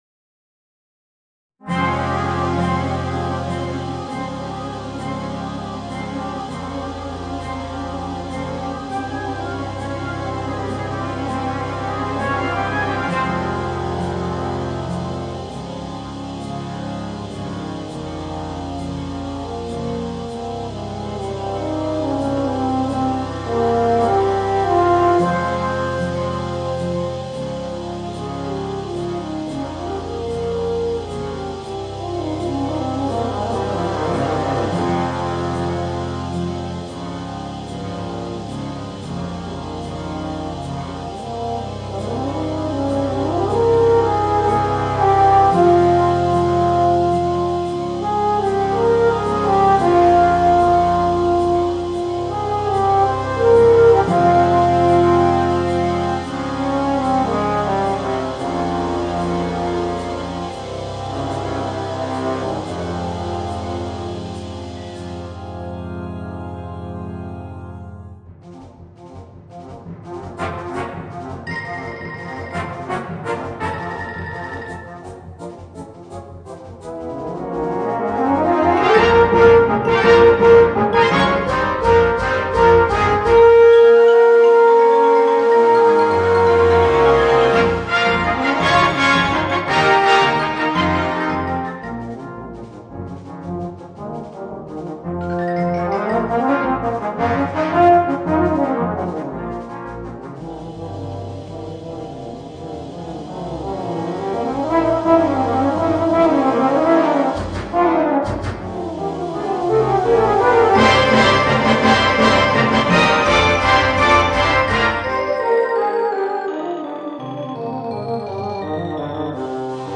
Voicing: Euphonium and Brass Band